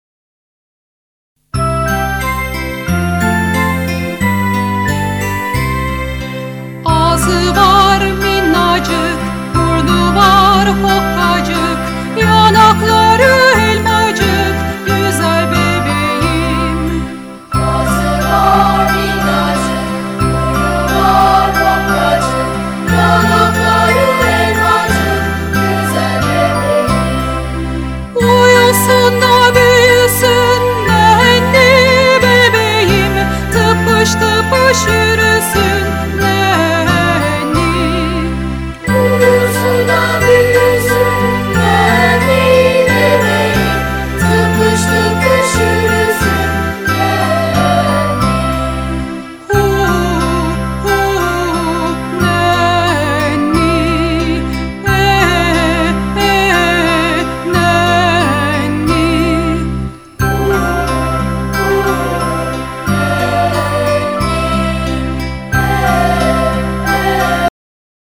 Çocuk Şarkıları